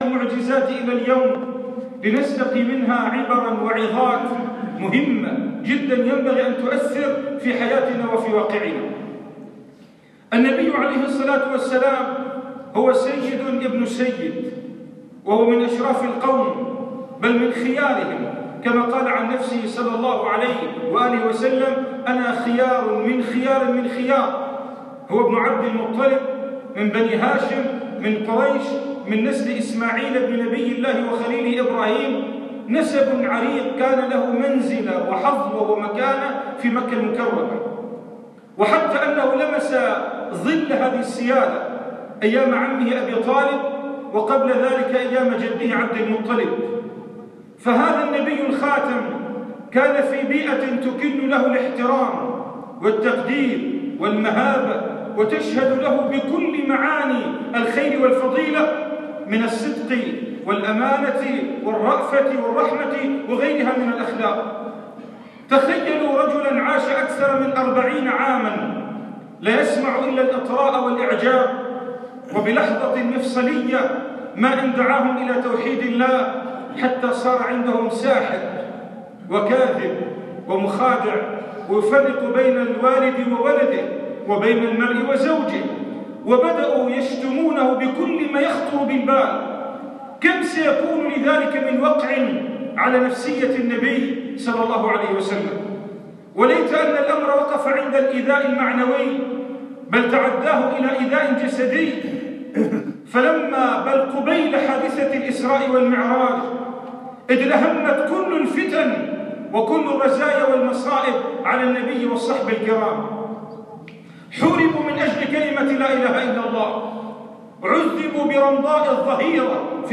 خطبة الجمعة
في جامع القلمون الكبير (البحري)